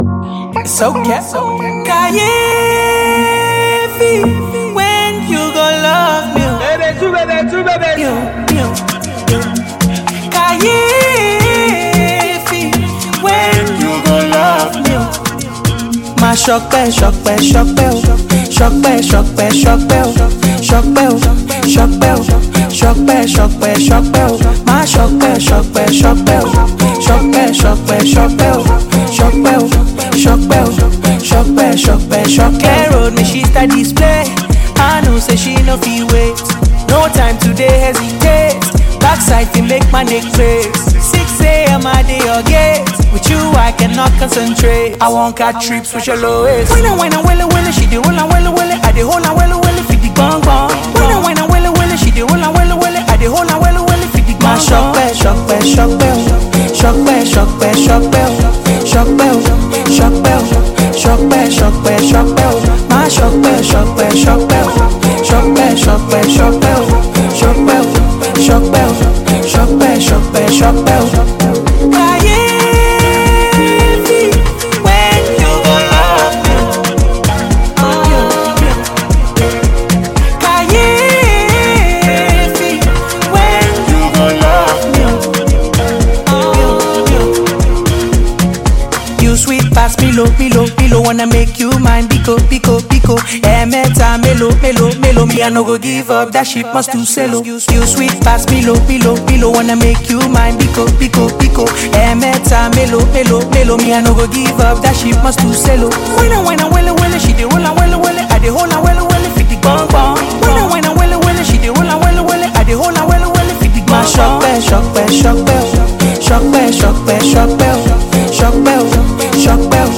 Veteran Nigerian afrobeats sensation